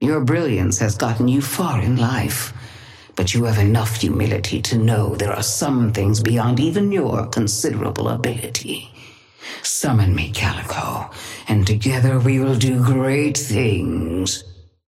Sapphire Flame voice line - Your brilliance has gotten you far in life.
Patron_female_ally_nano_start_04.mp3